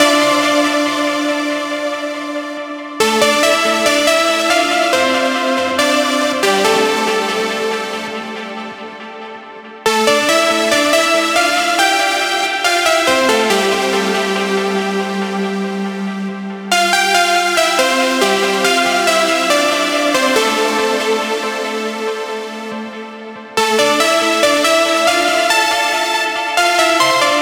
VTS1 25 Kit Melody & Synth